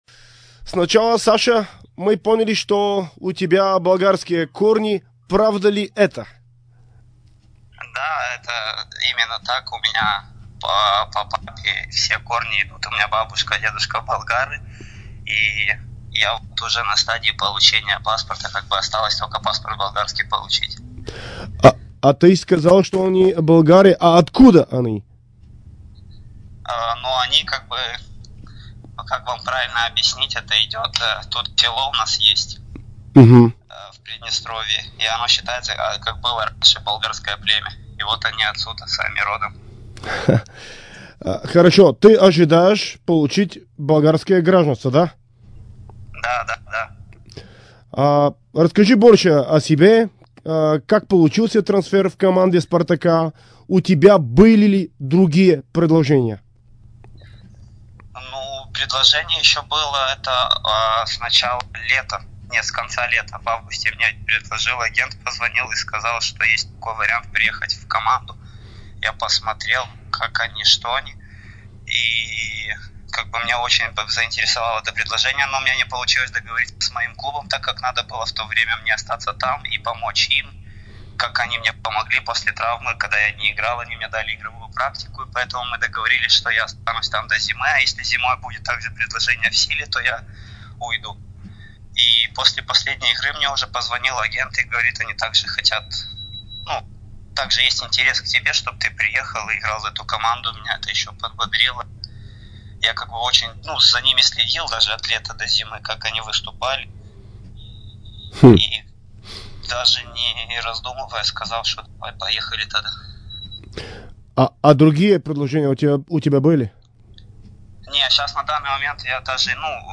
Ето какво сподели Белоусов в интервю за Дарик радио и dsport: